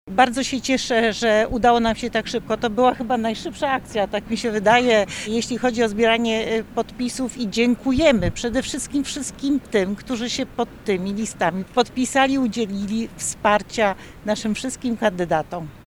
Konferencja prasowa odbyła się z udziałem wszystkich kandydatów i kandydatek KO.
Senator Barbara Zdrojewska podziękowała wszystkim, którzy udzielili wsparcia kandydatom.